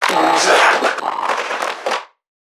NPC_Creatures_Vocalisations_Infected [117].wav